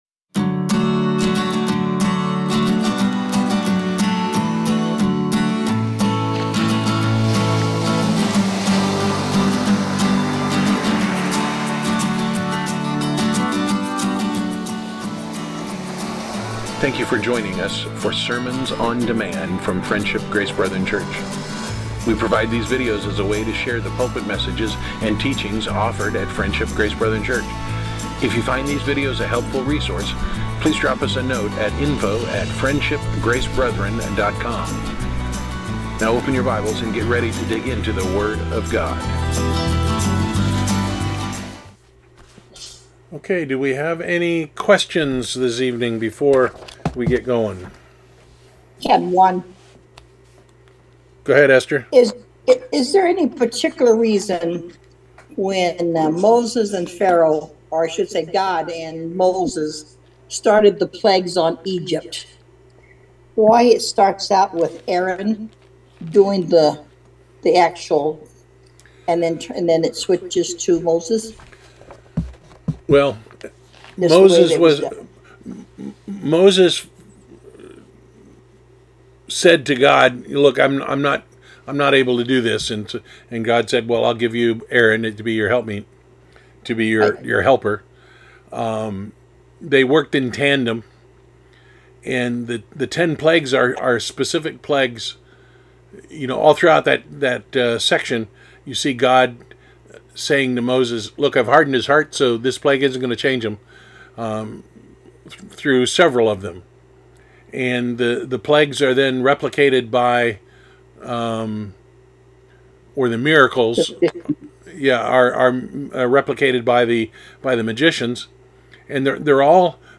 Topic: Weekly Bible Reading Discussion